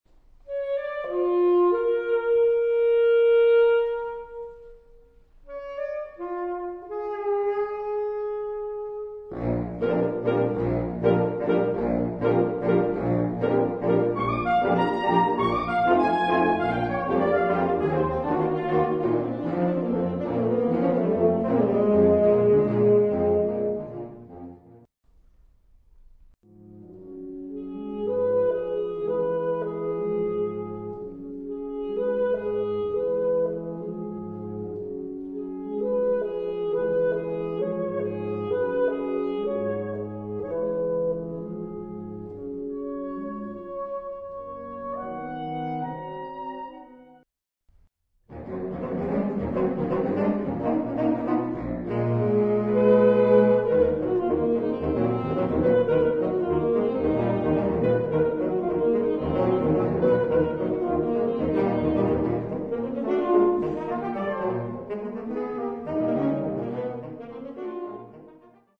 Partitions pour ensemble de saxophones, SoSAAATTTBBs.